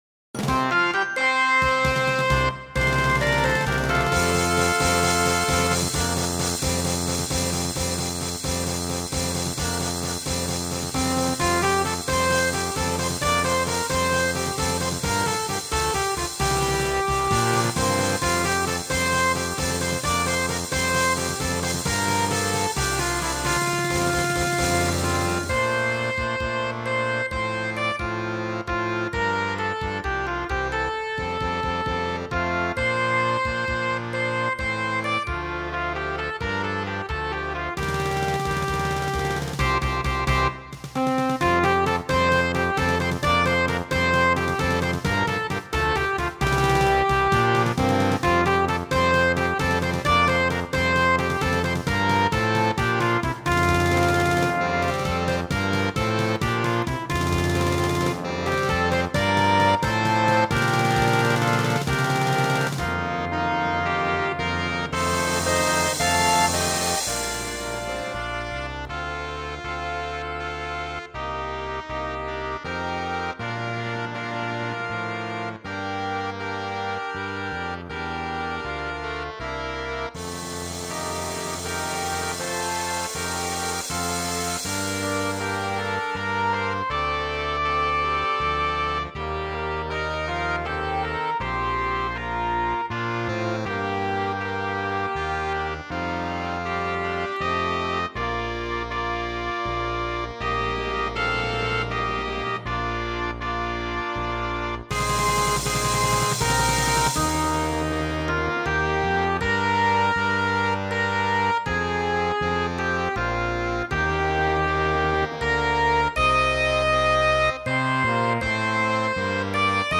Gattung: Flexible Besetzung
Besetzung: Blasorchester